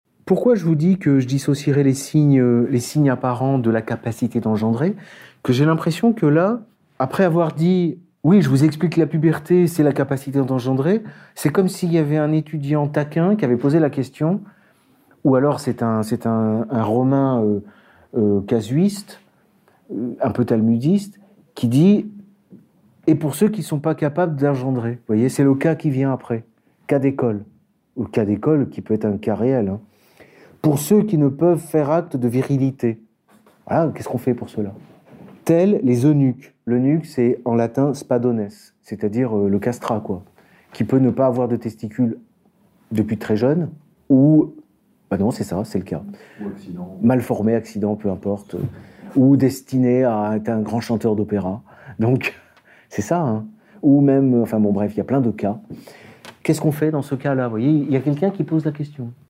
Le séminaire « la libération de la tutelle à la puberté » dure une heure, c’est le live d’un cours de droit que j’ai délivré dans le cadre des Formations d’Egalité et Réconciliation.